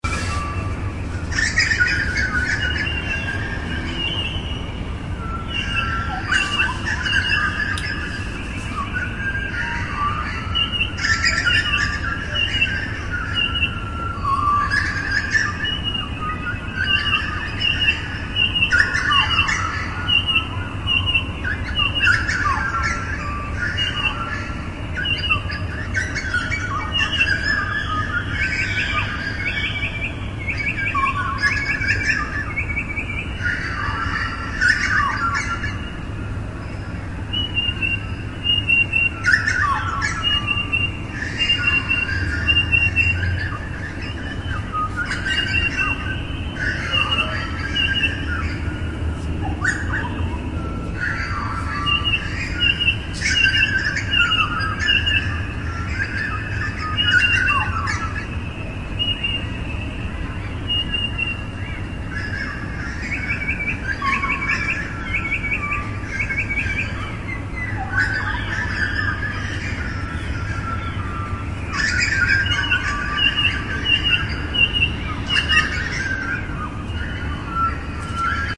Field Recordings » stuart lake dawn chorus june 30 2017
标签： birdsong birds dawn ambience ambient forest outside chorus morning quiet washington nature relaxing fieldrecording geotagged
声道立体声